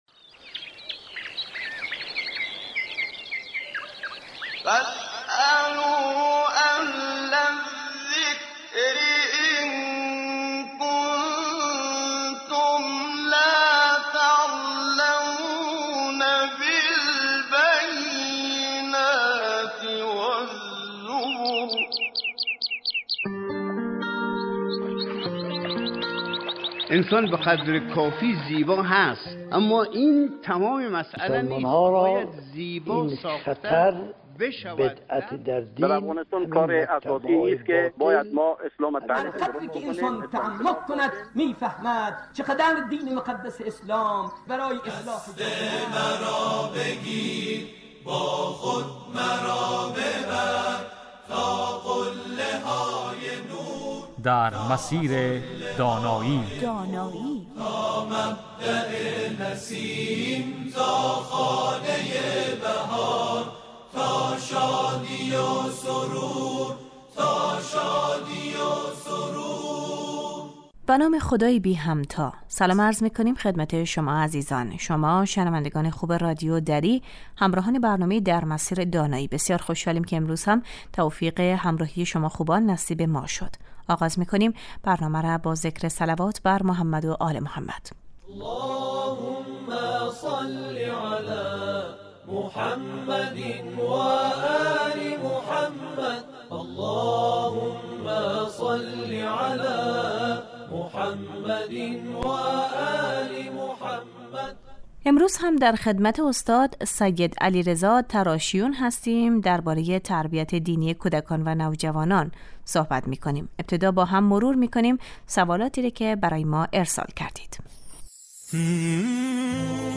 این برنامه 20 دقیقه ای هر روز بجز جمعه ها ساعت 11:35 از رادیو دری پخش می شود